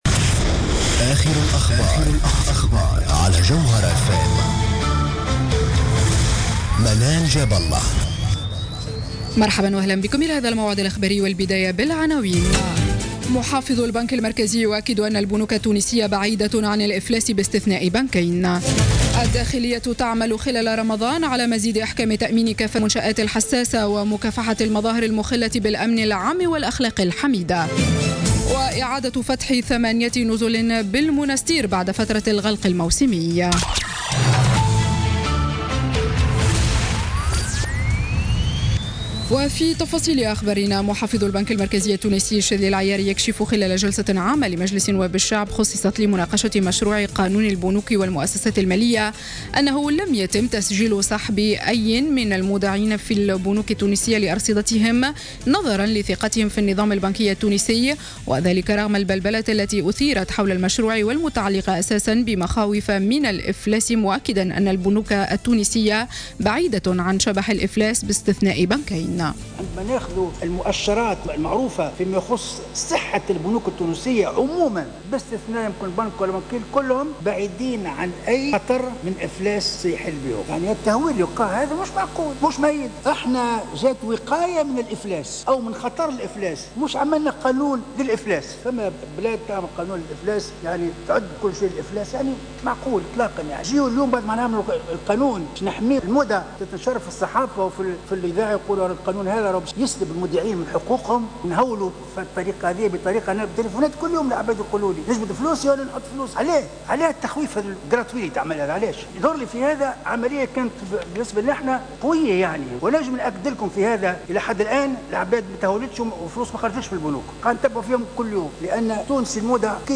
نشرة أخبار الخامسة مساء ليوم الثلاثاء 7 جوان 2016